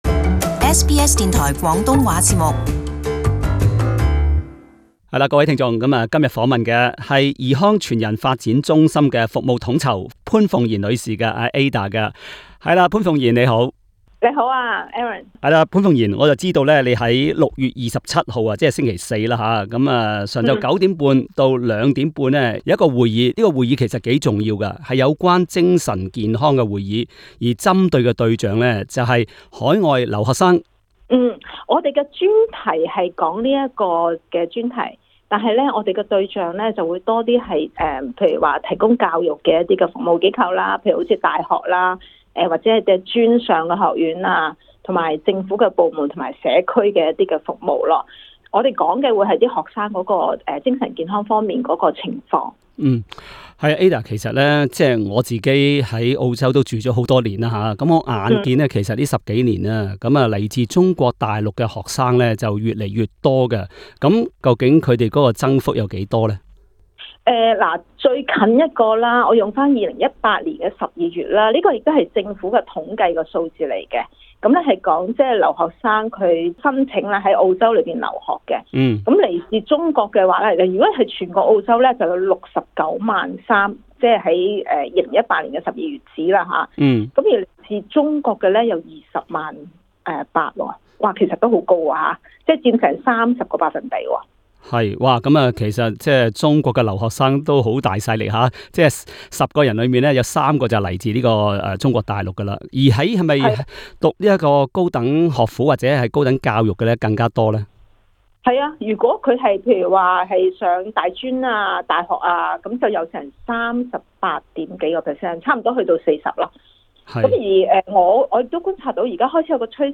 【社區專訪】海外留學生容易出現精神健康問題